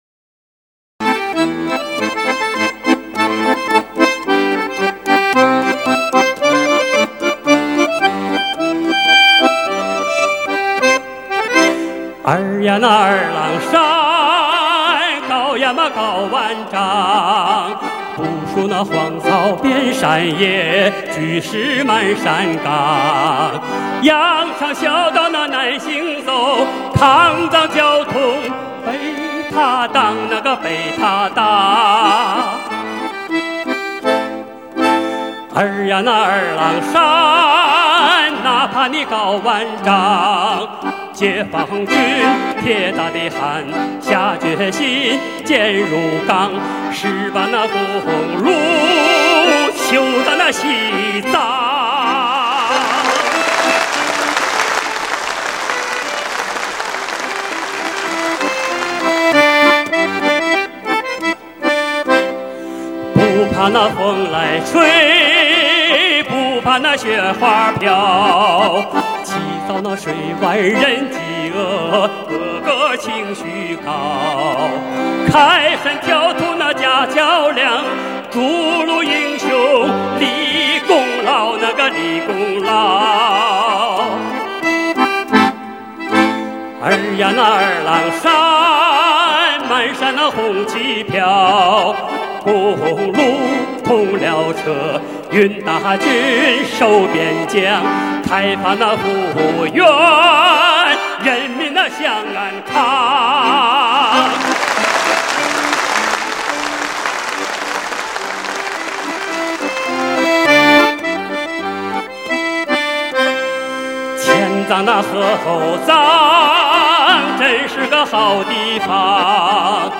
手风琴伴奏
2007年演出实况录音